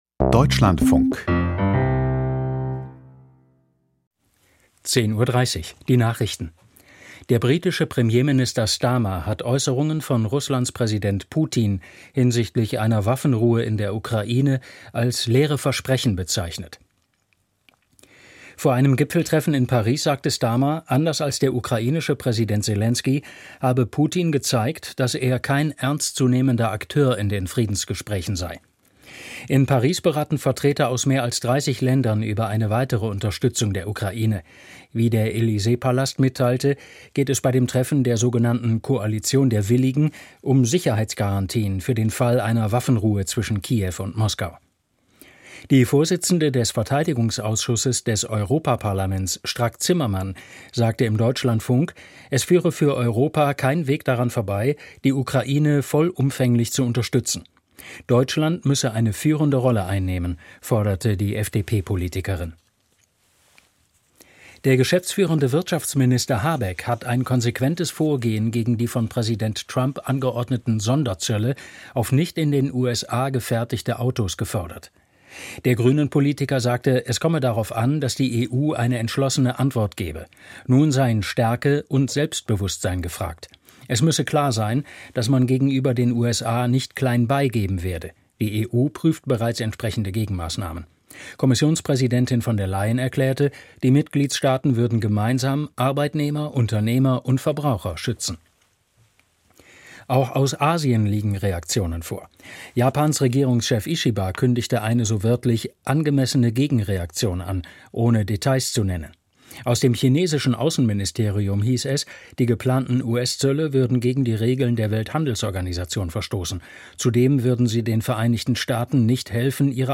Die Deutschlandfunk-Nachrichten vom 27.03.2025, 10:30 Uhr